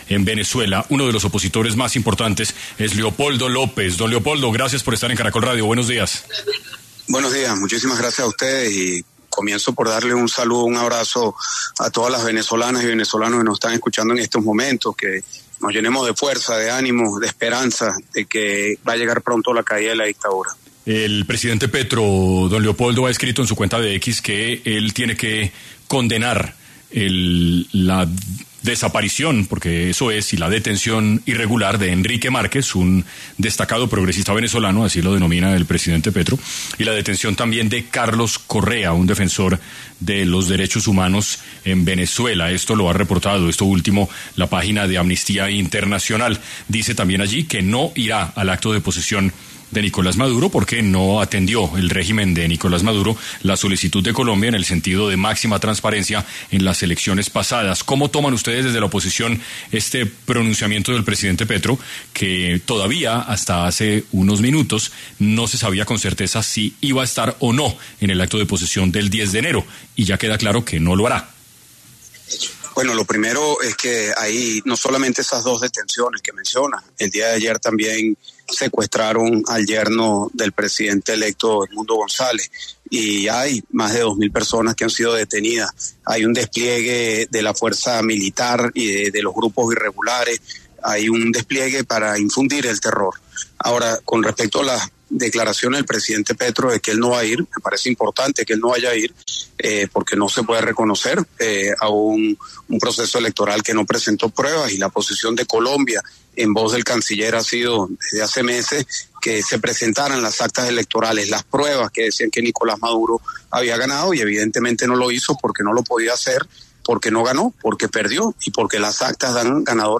Leopoldo López, líder de la oposición venezolana, habló en 6AM, cómo reciben la confirmación de Gustavo Petro de no asistir a la posesión de Nicolás Maduro